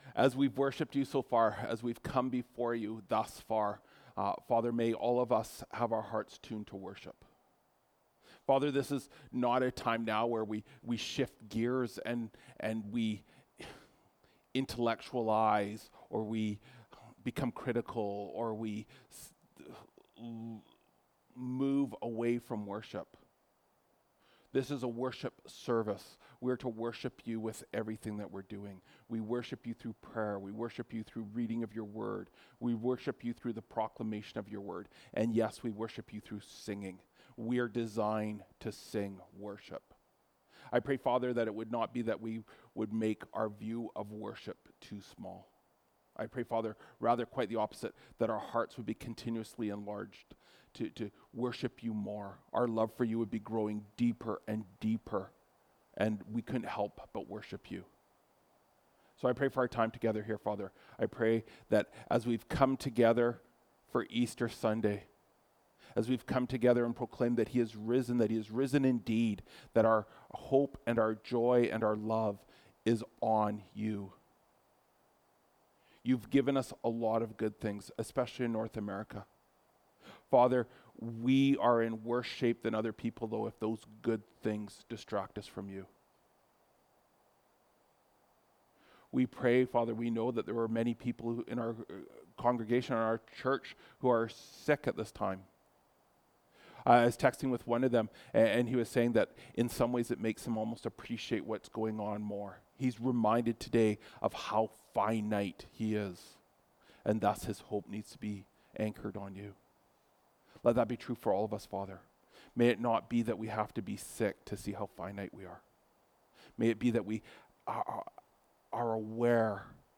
Mar 31, 2024 From Death to Life (Matthew 28:1-20) MP3 SUBSCRIBE on iTunes(Podcast) Notes Discussion Sermons in this Series This sermon was preached and recorded at Grace Church - Salmon Arm and also preached at Grace Church - Enderby.